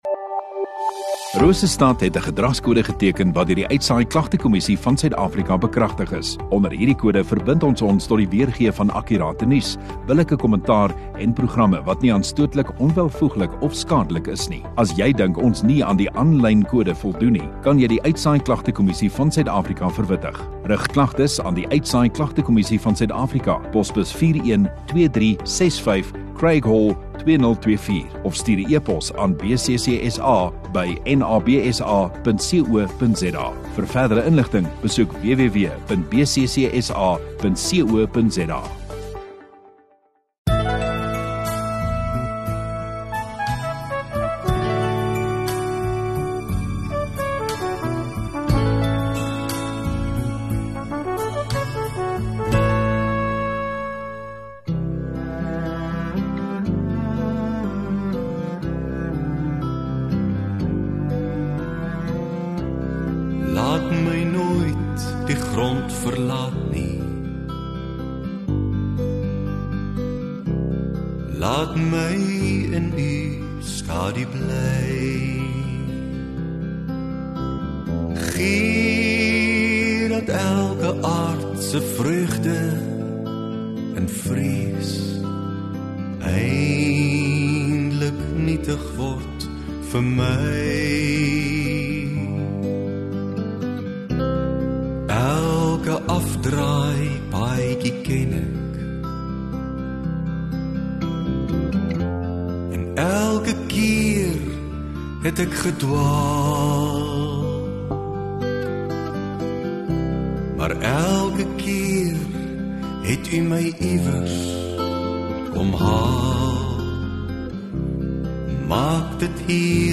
21 Sep Sondagoggend Erediens